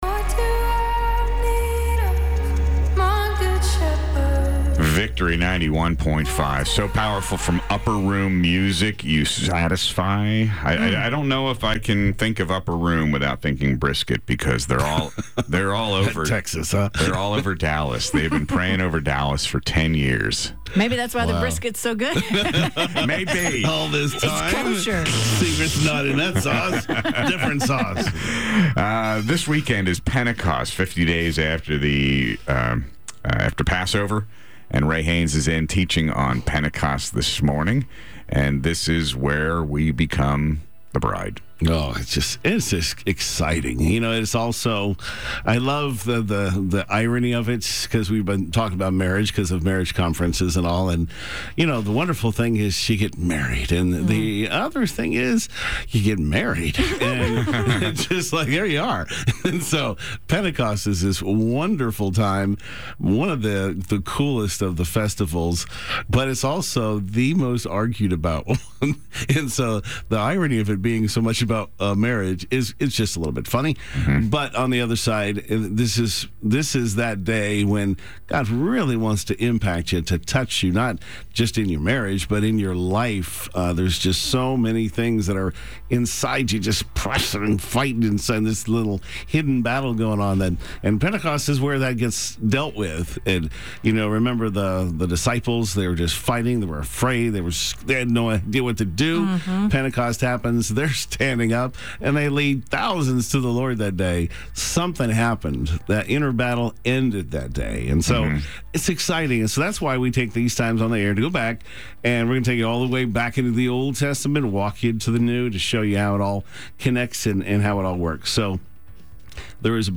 Live on-air clips and teaching notes